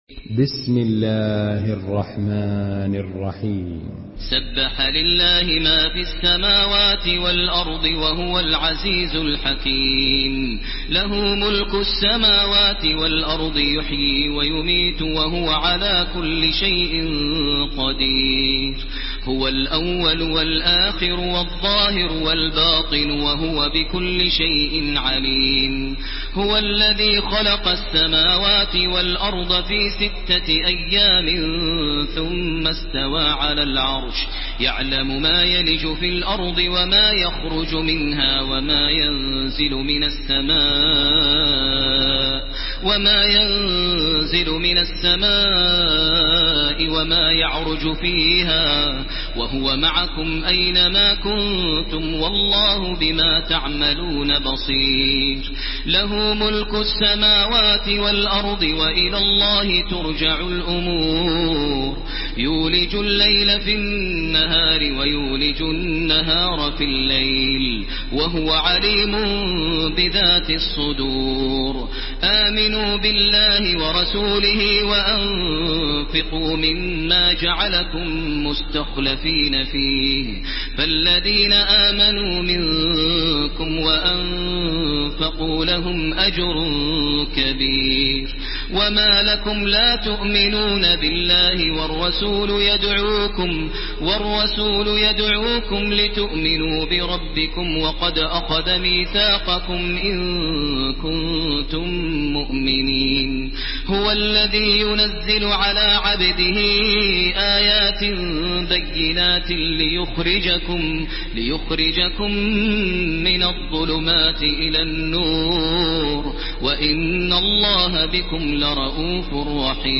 Surah Hadid MP3 by Makkah Taraweeh 1430 in Hafs An Asim narration.
Murattal